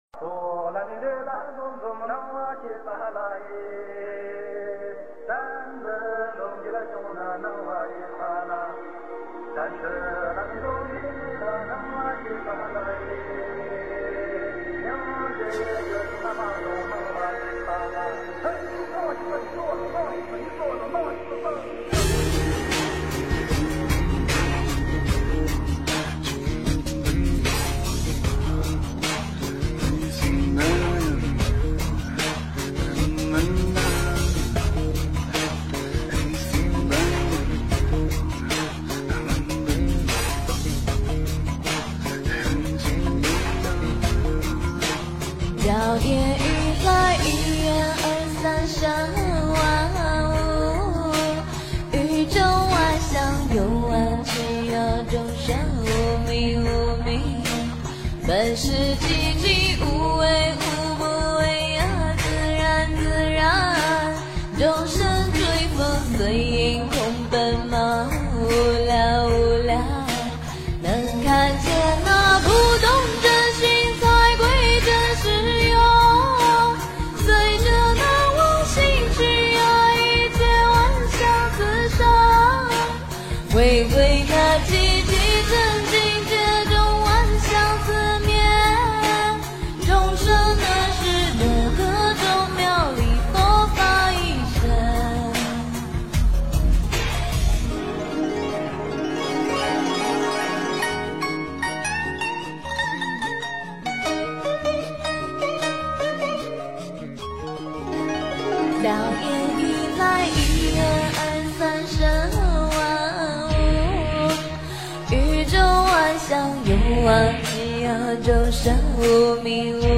佛教音乐
标签: 佛音诵经佛教音乐